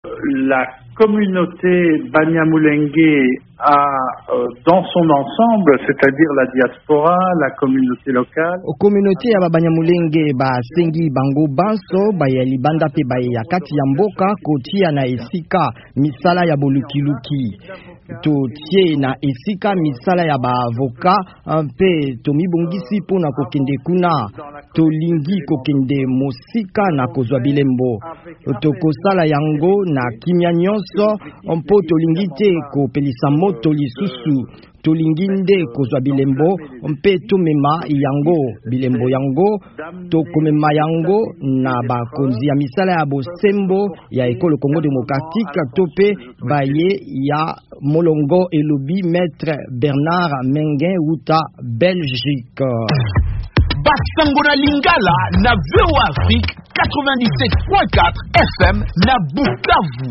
Communauté ya ba Banyamulenge ezwi ba avocats mpe esengi bango komema bobomami na Minembwe (Sud-Kivu) na bazuzi. VOA Lingala ebengaki na singa wuta Belgique